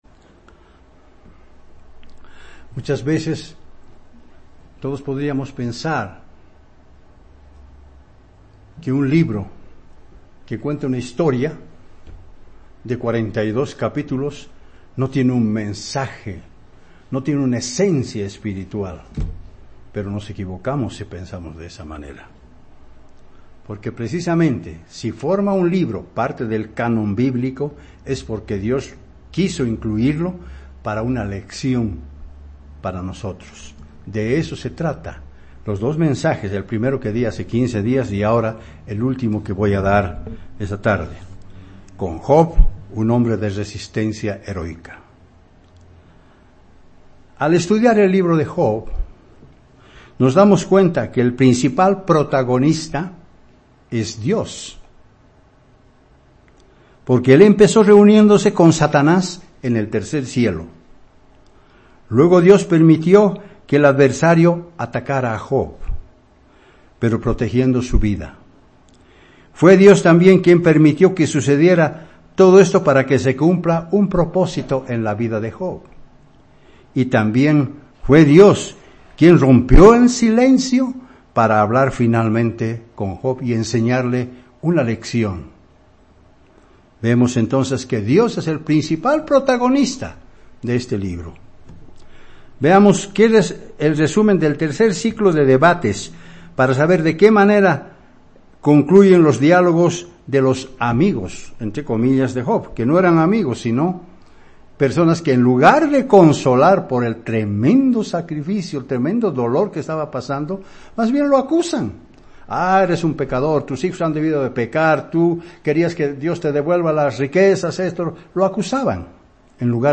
Sermones
Given in La Paz